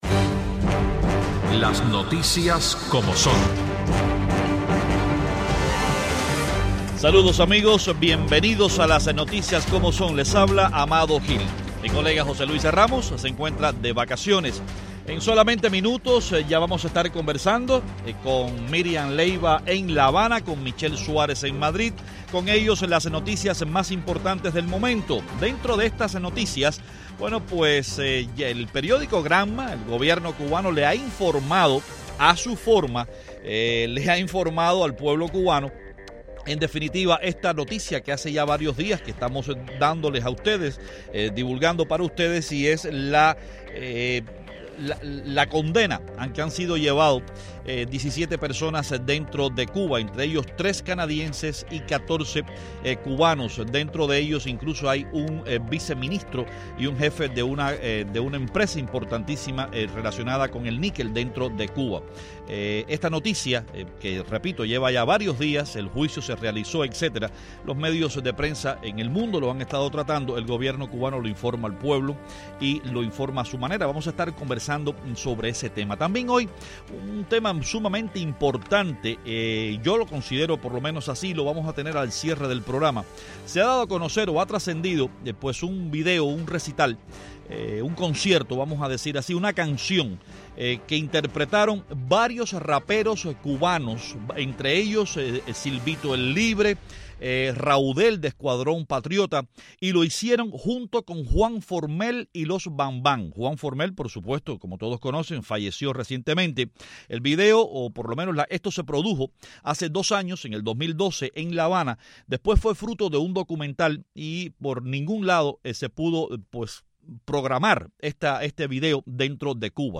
Los periodistas